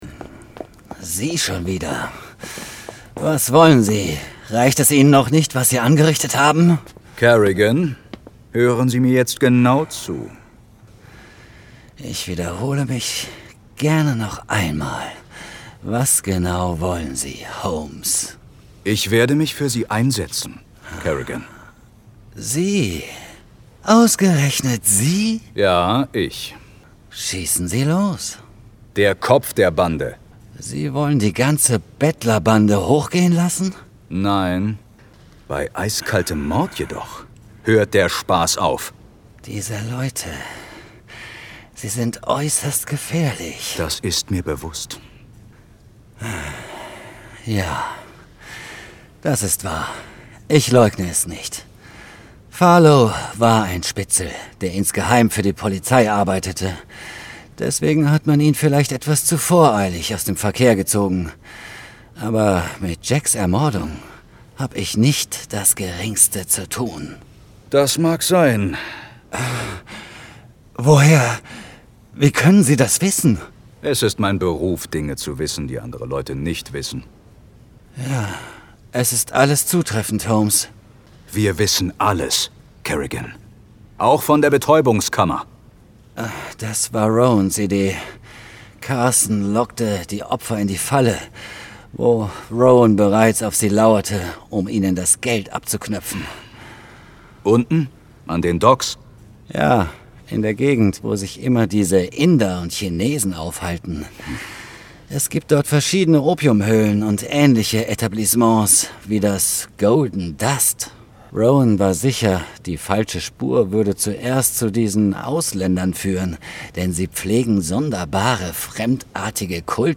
sehr variabel
Overlay, Doku